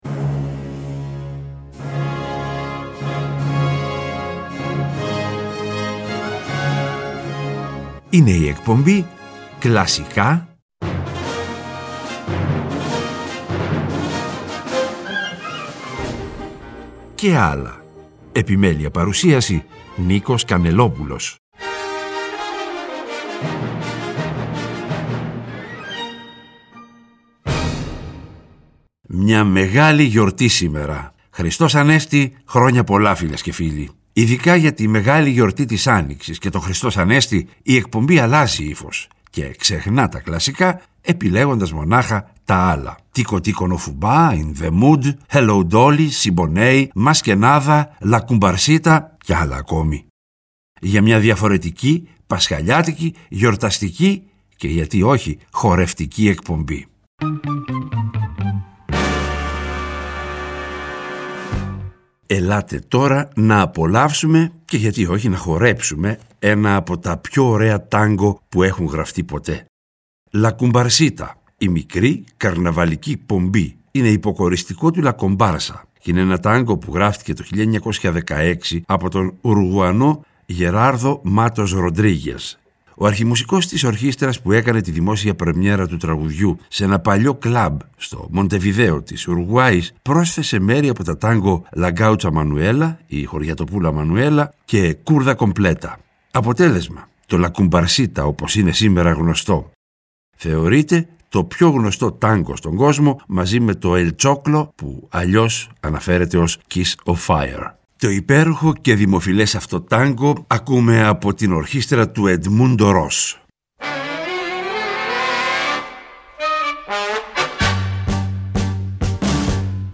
Για μια διαφορετική, πασχαλιάτικη, γιορταστική και -γιατί όχι- χορευτική εκπομπή.